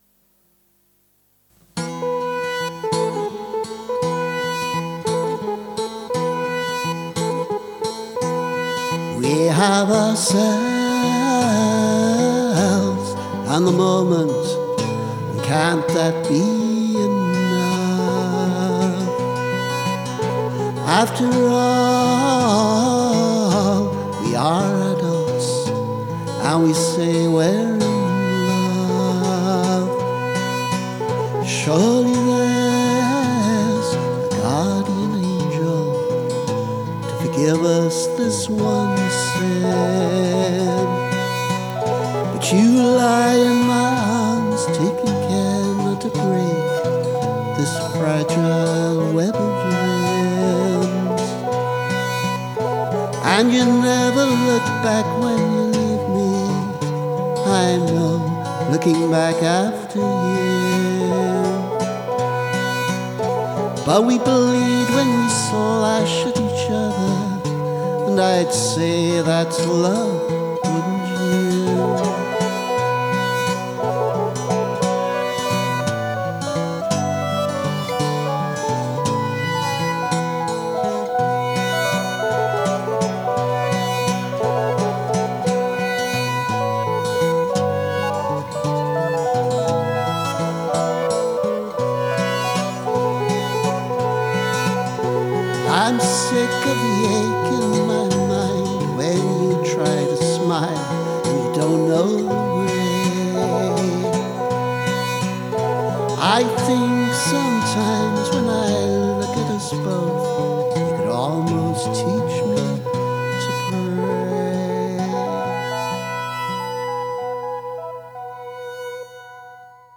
Something a little more experimental than usual. Well, more synth and dissonance, anyway. Underneath, there’s quite a simple song.
Guitar, vocals, synth